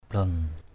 /blɔn/ (t.) lồi. mata blaon mt% _b*<N mắt lồi. blaon tabiak _b*<N tb`K lồi ra.
blaon.mp3